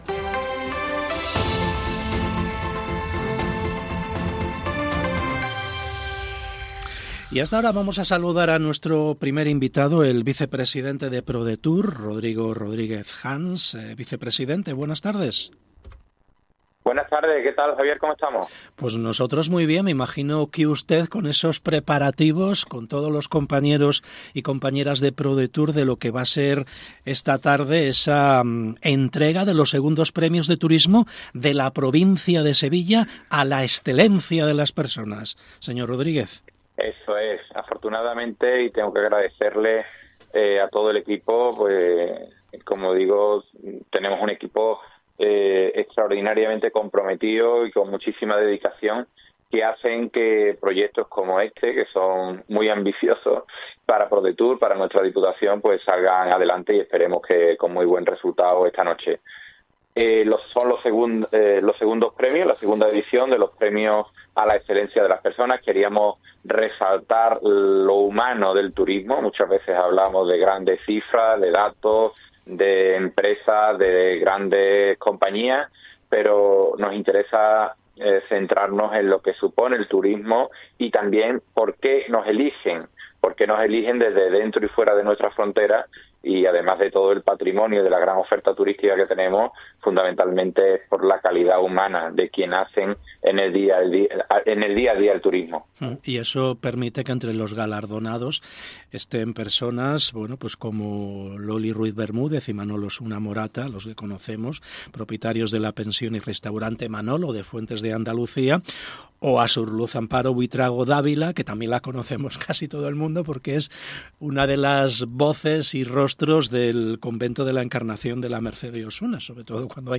ENTREVISTA RODRIGO RODRIGUEZ HANS DIA DEL TURISMO
Rodrigo Rodríguez Hans, Vicepresidente de PRODETUR, ha pasado por Hoy por Hoy SER Andalucía Centro.